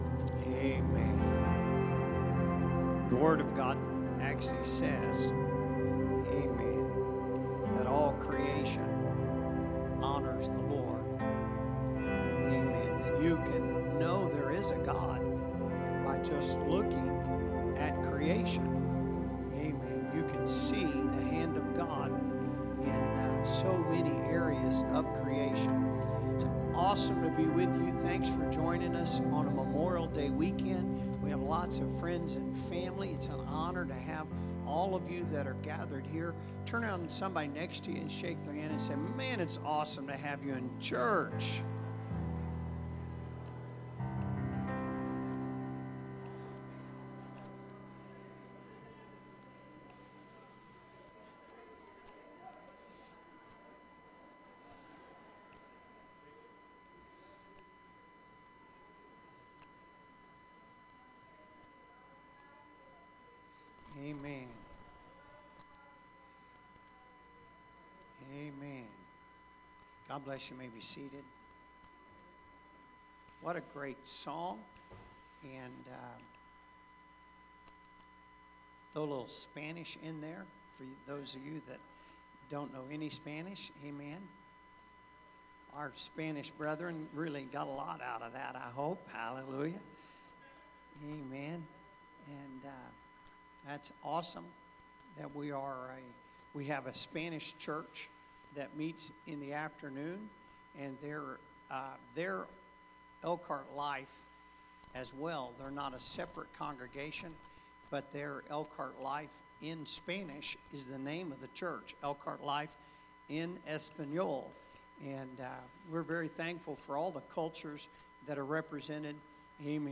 Sermons | Elkhart Life Church